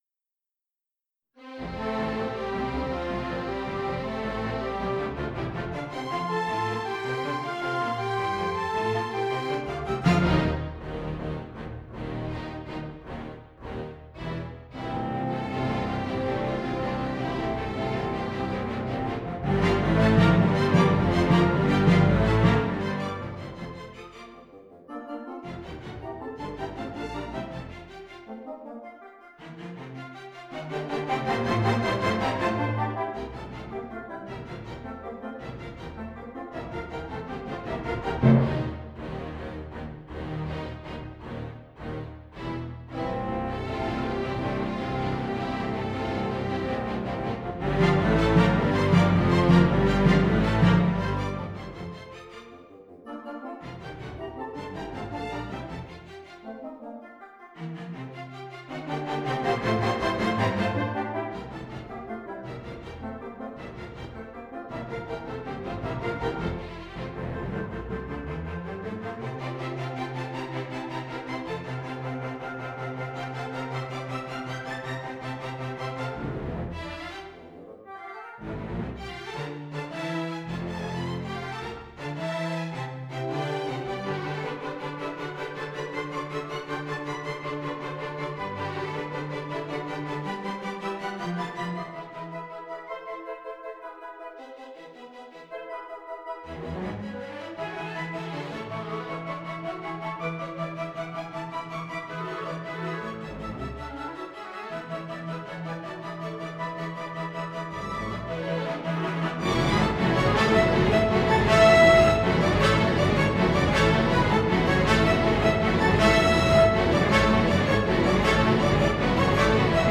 I think your Op135 scherzo would probably make the best effect if performed in isolation.
Beeth-op135-2-orch.mp3